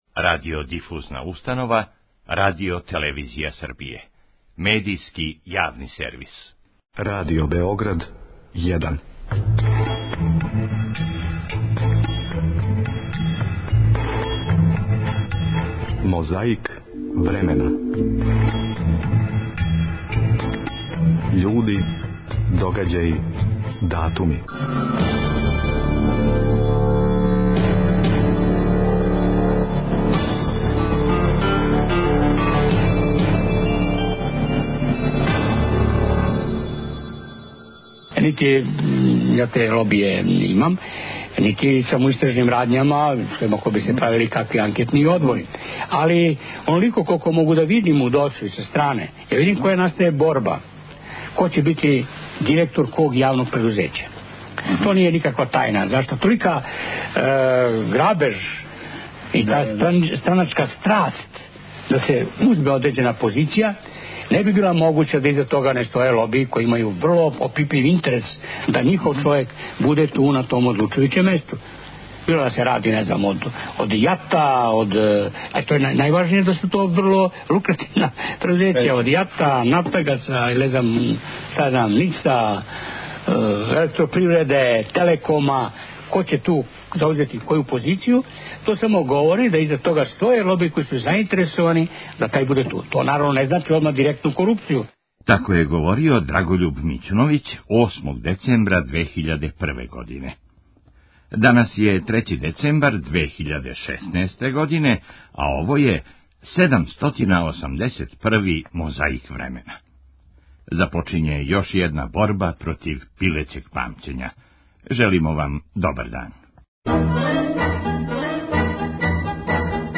Прeдсeднички кaндидaт СПO Вук Дрaшкoвић биo je гoст Првoг прoгрaмa Рaдиo Бeoгрaдa 4. дeцeмбрa 1990. гoдинe.